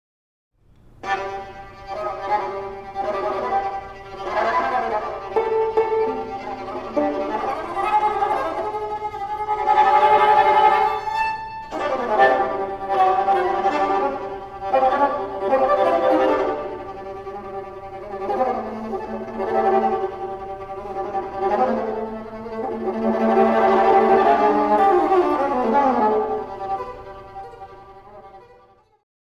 viola, cello or violin